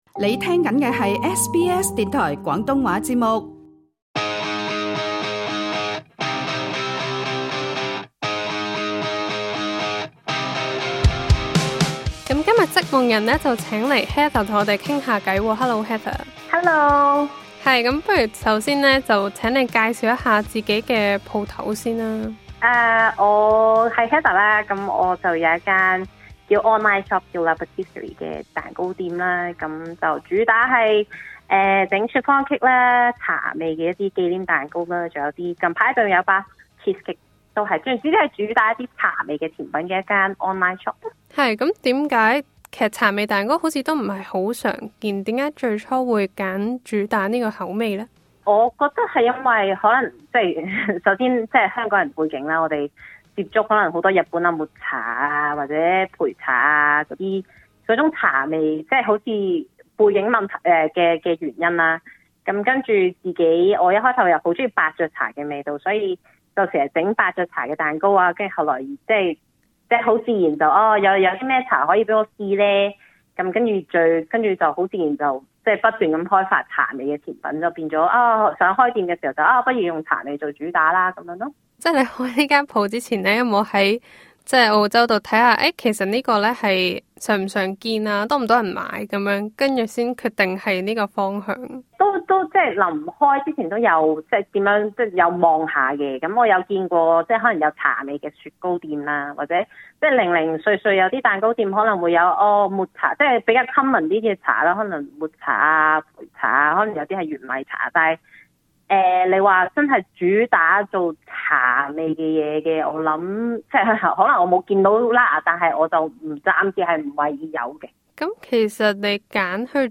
更多詳情請收聽足本訪問: